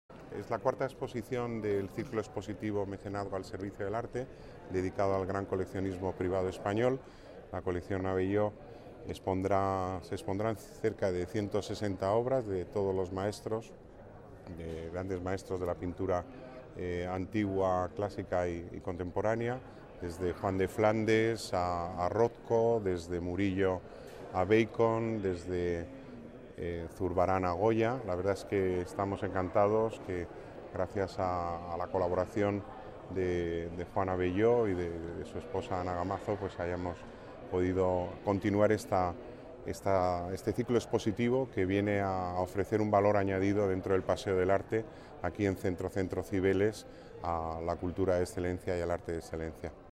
Nueva ventana:Declaraciones del delegado de Las Artes, Pedro Corral: Exposición Colección Abelló